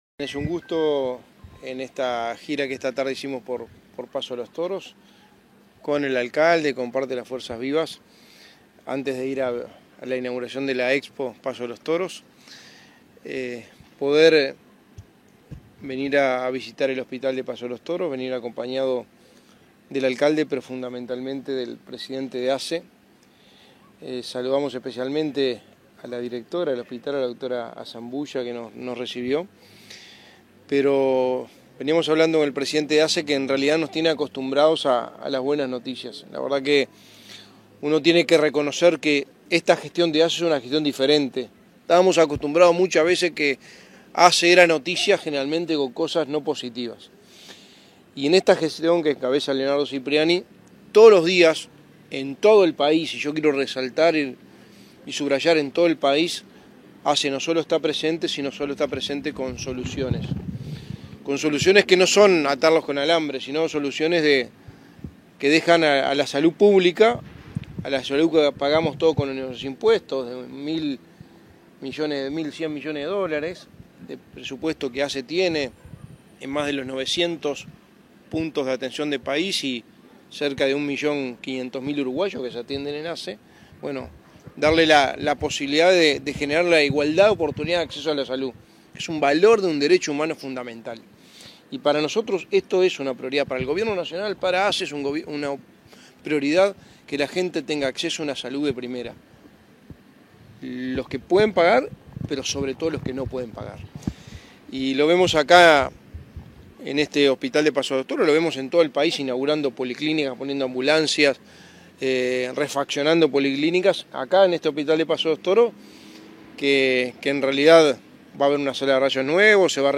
Declaraciones del secretario de la Presidencia, Álvaro Delgado, y del presidente de ASSE, Leonardo Cipriani
Declaraciones del secretario de la Presidencia, Álvaro Delgado, y del presidente de ASSE, Leonardo Cipriani 10/02/2023 Compartir Facebook X Copiar enlace WhatsApp LinkedIn Tras visitar el hospital de Paso de los Toros, este 9 de febrero, el secretario de la Presidencia, Álvaro Delgado, y el presidente de la Administración de los Servicios de Salud del Estado (ASSE), Leonardo Cipriani, realizaron declaraciones a la prensa.